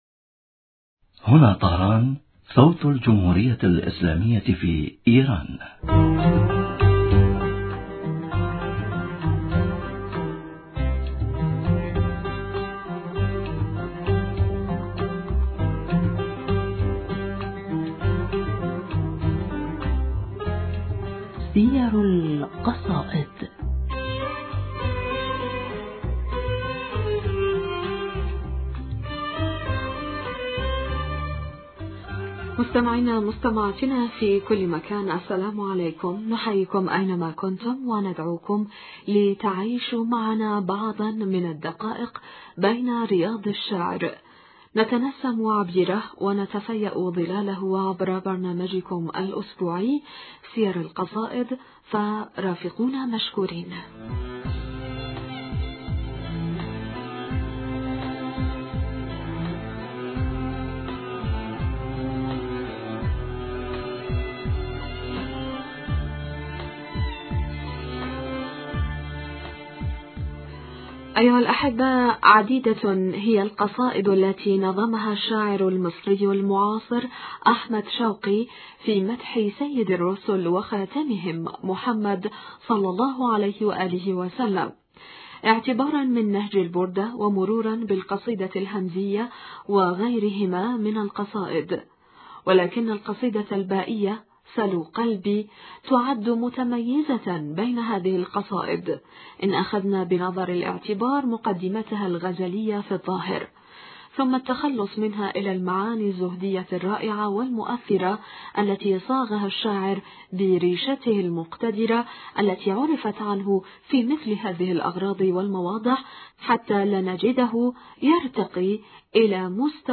المحاورة